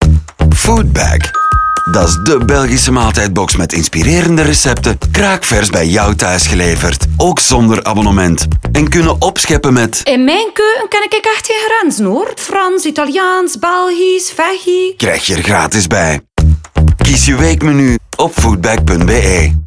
Een eenvoudige, maar doeltreffende ‘dingdong’-bel werd het symbool van de Foodbag-campagne.
De deurbel is kort en karakteristiek, en dient als een soort leesteken in de communicatie, wat zorgt voor een subtiel en ritmisch ritme in de campagnespots.